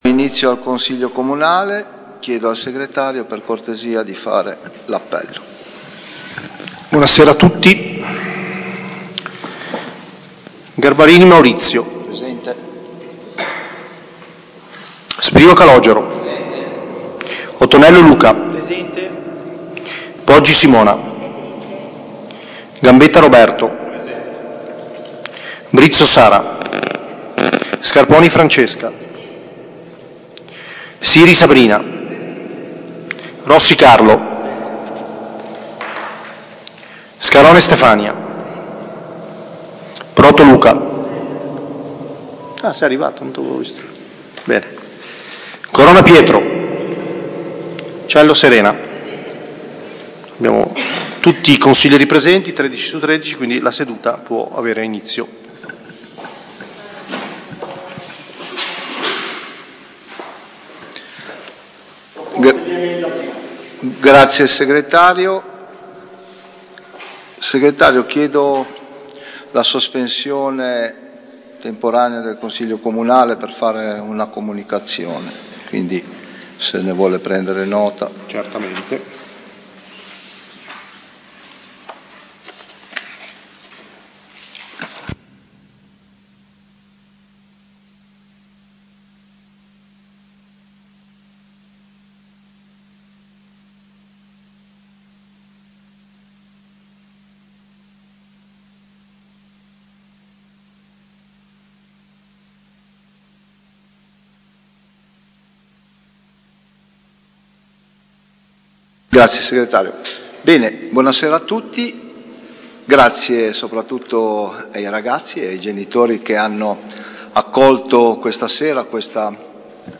Consiglio comunale del 9 febbraio 2026 - Comune di Albisola Superiore
Seduta del Consiglio comunale lunedì 9 febbraio 2026, alle 20.30, presso l'Auditorium comunale in via alla Massa.
Registrazione-audio-del-Consiglio-Comunale-del-9-febbraio-2026.mp3